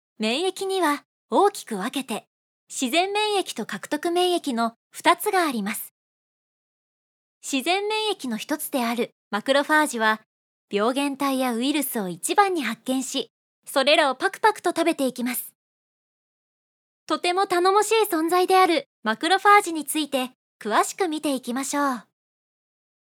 I strive for a clear voice that is natural and easy to listen to.
My moist, soft and deep voice is packed with the youthful brightness that only someone in their 20s can have.
– Narration –
Straight